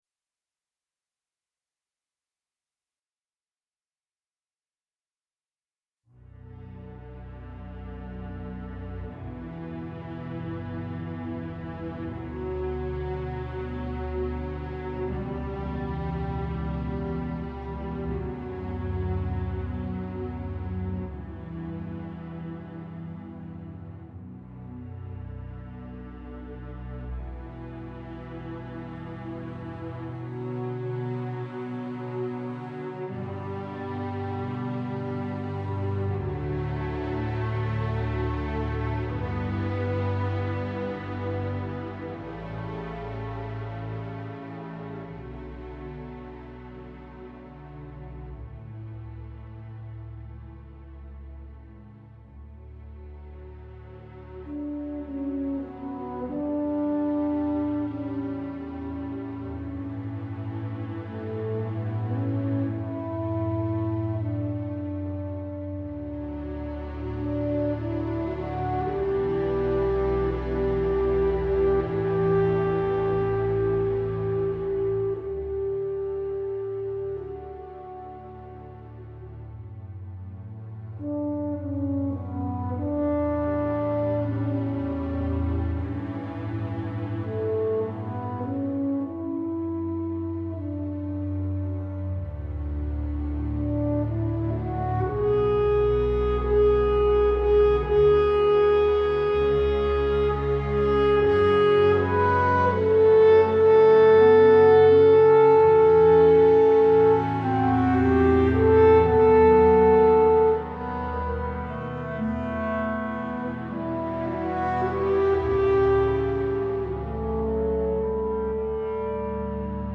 I've recorded an excerpt from Tchaikovsky's Symphony No. 5 -- the opening of the Andante (with the famous french horn solo):
This is my first use of the new French Horn instrument.
The same on all instruments, but with different settings for early reflections, etc. corresponding to a typical stage placement.
Vienna Instruments
First, they are the only instruments playing with mutes. Muted strings are very quiet.
The difference between the softest and loudest moments in this recording is quite large, but still not as large as a real orchestra in a concert hall.